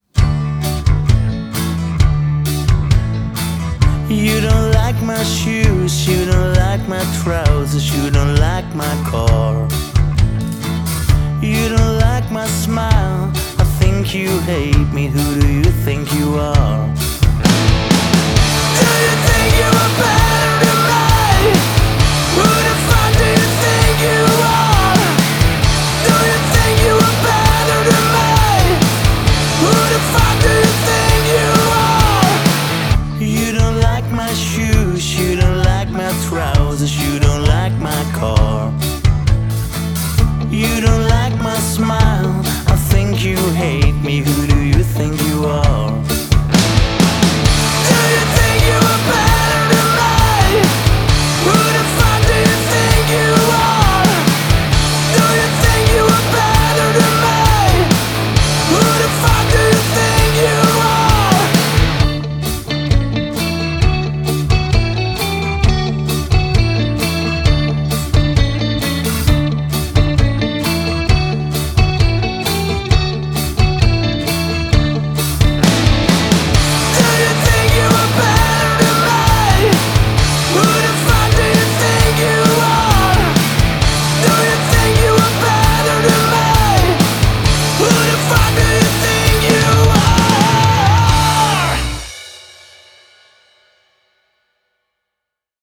Are you ready to rock?